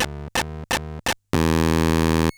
Glitch FX 47.wav